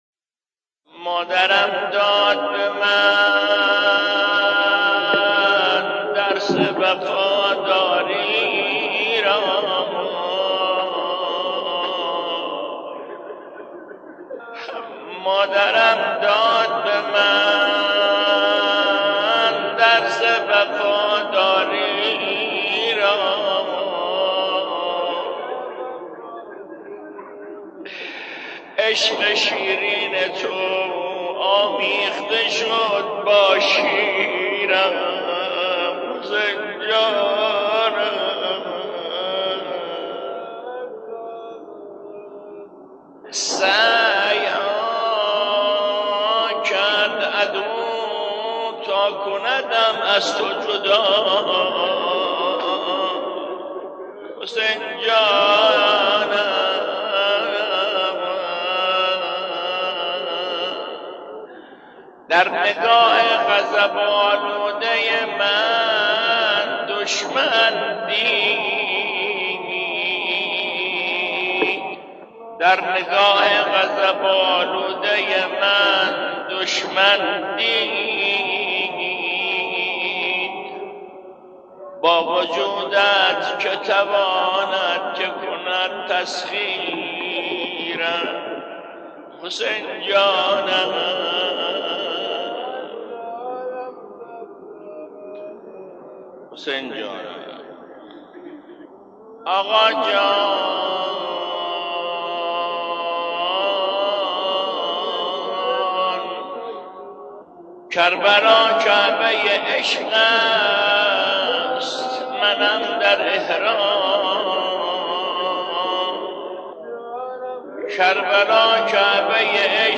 صدای ماندگار مداحان دیروز/ ۱۱